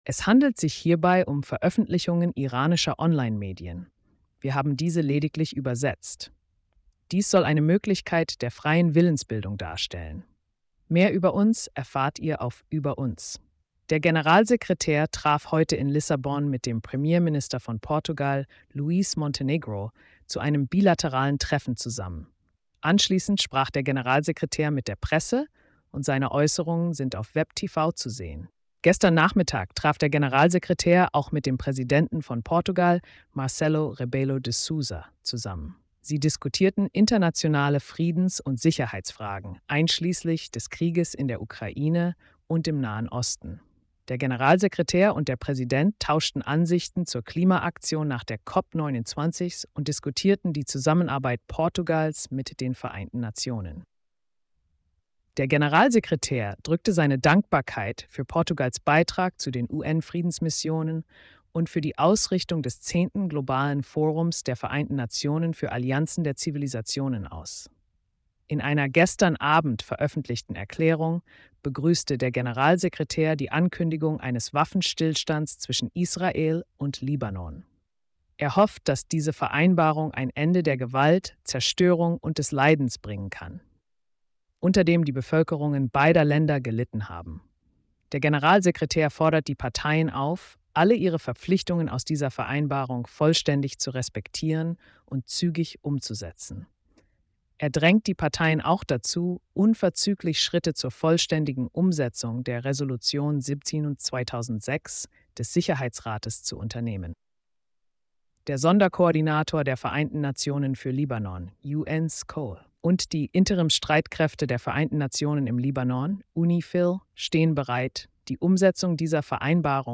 Spannende Themen: Portugal, Libanon, Israel & mehr – Tägliche Pressekonferenz (27. November 2024) | Vereinte Nationen